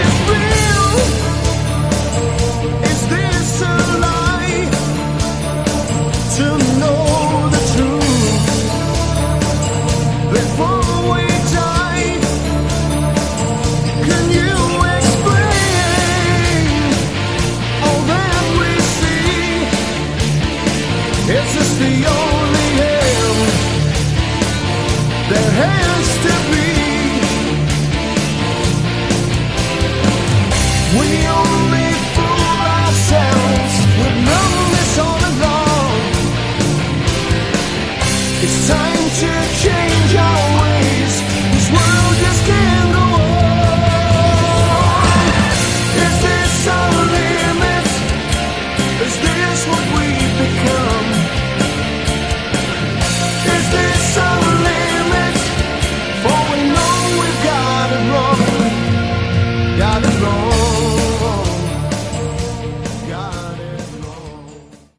Category: AOR
Straight-ahead British A.O.R. ,No frills,good album..